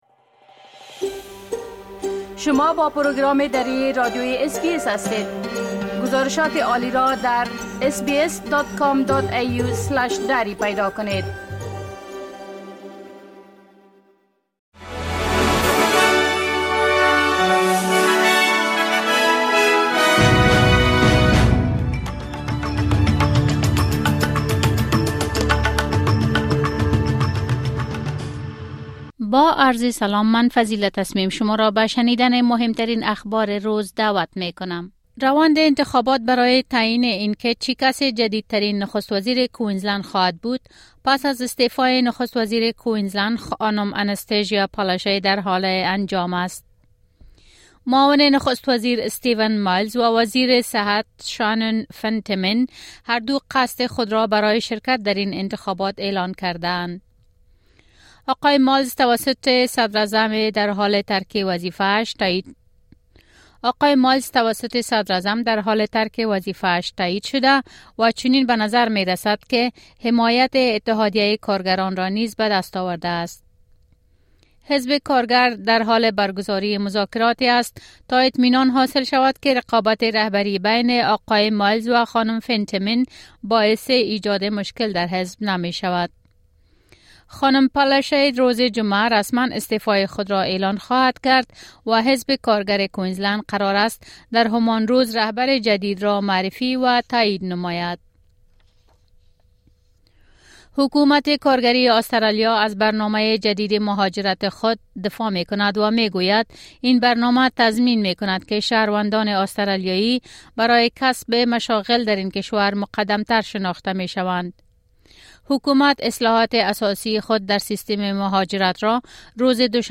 اخبار مهم روز از اس‌بی‌اس دری